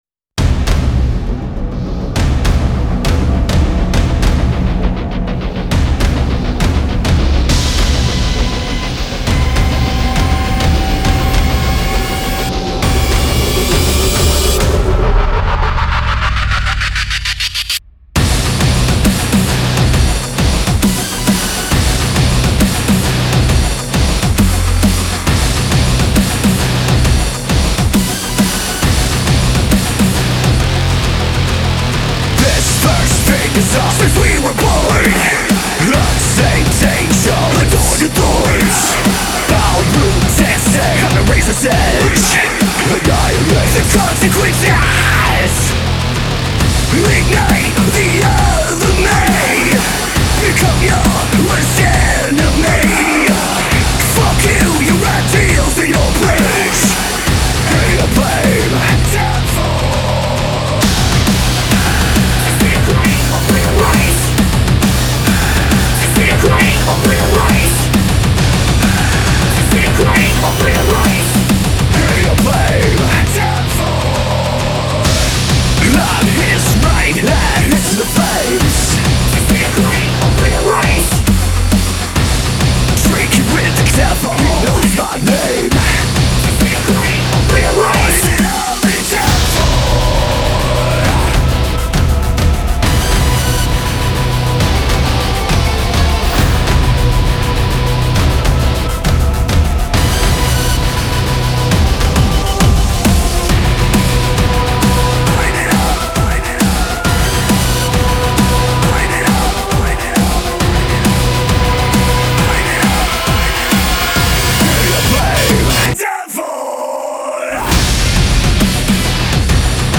industrial gothic rock